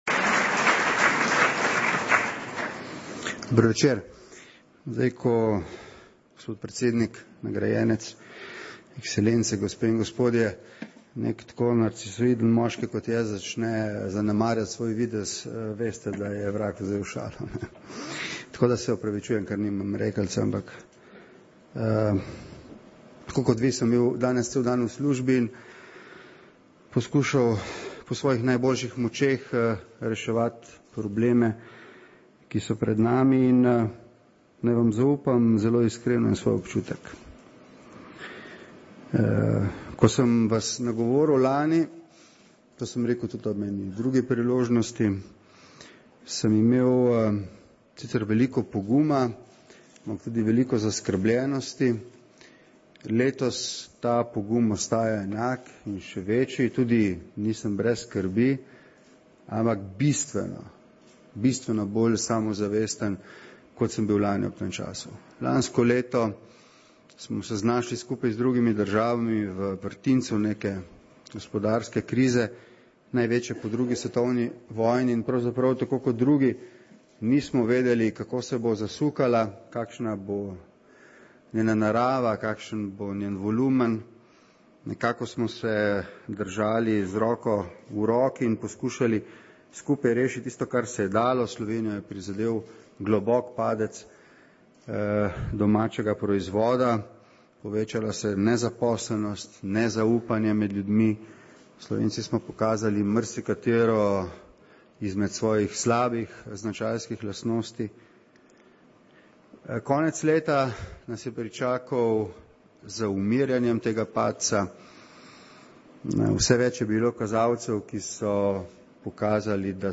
Premier Borut Pahor se je udeležil januarskega srečanja Združenja Manager
Predsednik Vlade Republike Slovenije Borut Pahor se je udeležil januarskega srečanja Združenja Manager, kjer je zbrane člane združenja in druge goste tudi nagovoril. Premier Pahor je dejal, da verjame v to, da Slovenija lahko iz krize izide kot zmagovalka ter zagotovil, da vlada z ukrepi, ki jih pripravlja ne bo obremenjevala gospodarstva.